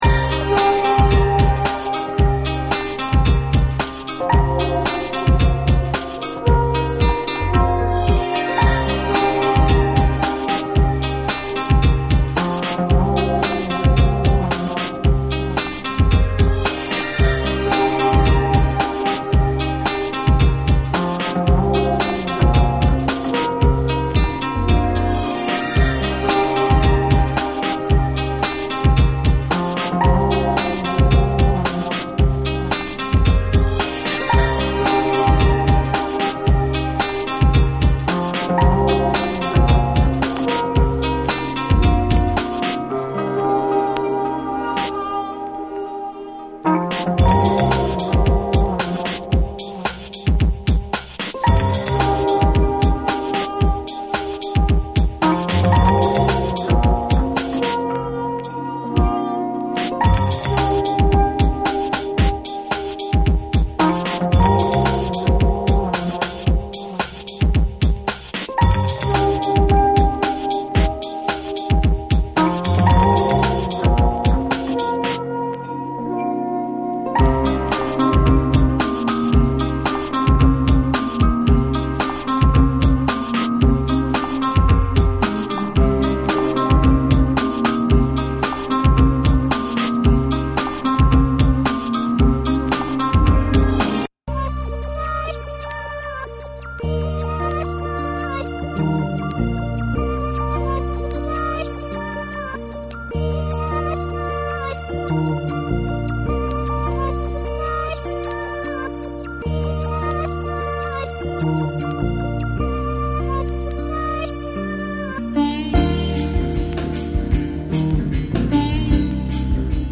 優美なピアノと無国籍ヴォイスがクールな2。ジャジーな要素を取り入れた心地よいアブストラクト・ブレイクビーツの3。
BREAKBEATS / CHILL OUT